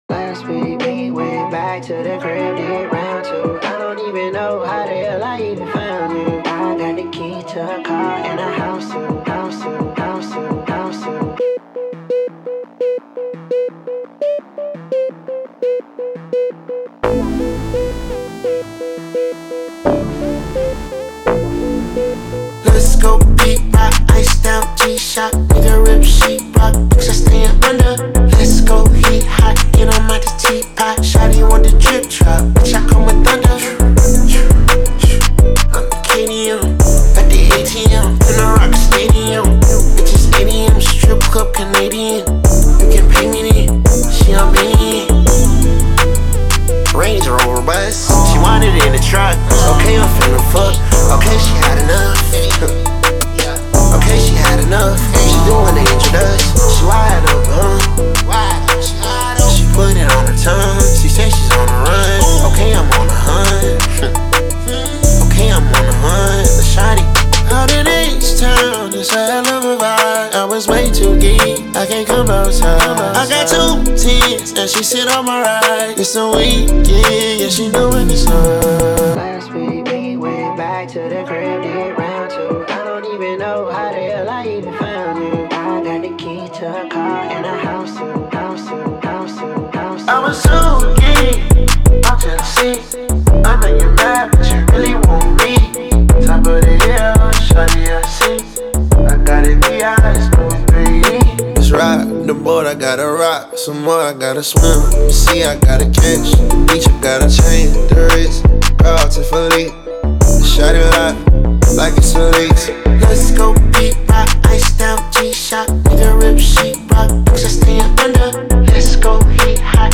Трек размещён в разделе Рэп и хип-хоп / Зарубежная музыка.